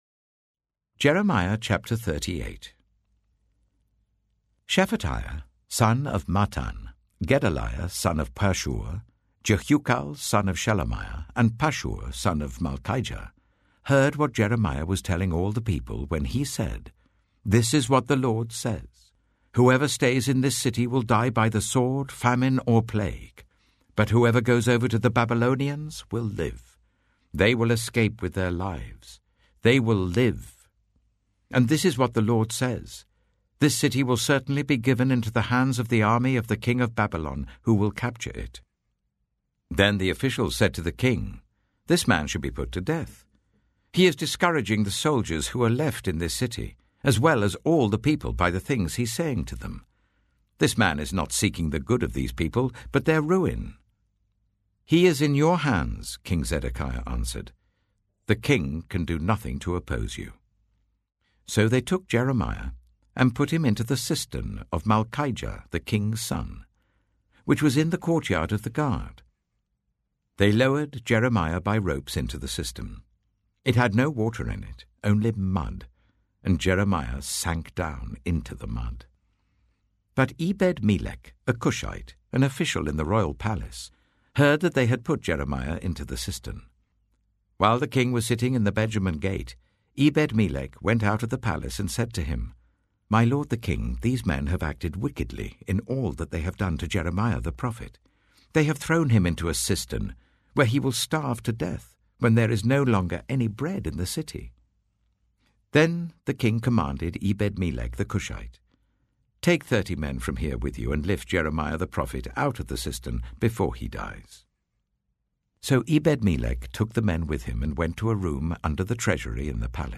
The reading for Year of the Bible, Day 235, is Lamentations 3-5: In this third lament, the acrostic starts three successive verses with each letter of the Hebrew alphabet. The fourth lament reports the suffering of the people of Jerusalem during the final days of the Babylonian siege.